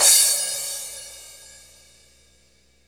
Index of /90_sSampleCDs/Roland L-CD701/KIT_Drum Kits 2/KIT_Dry Kit
CYM MAX C0UR.wav